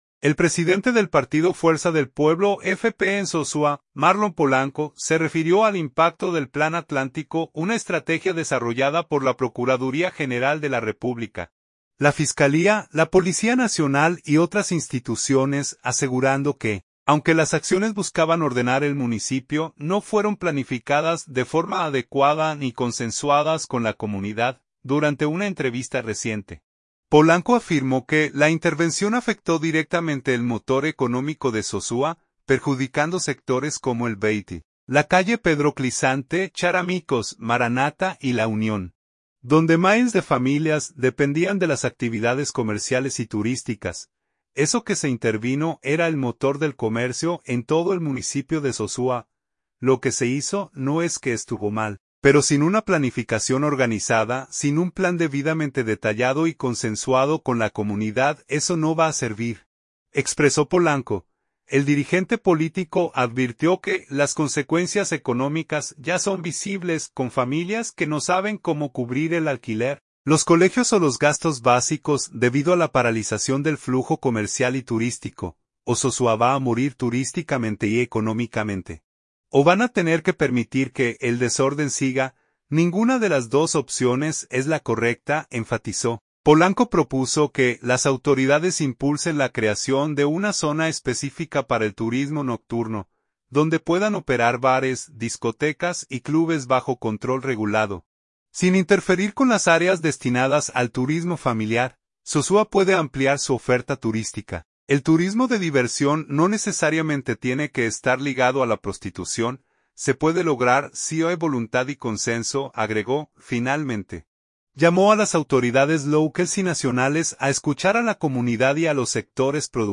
Durante una entrevista reciente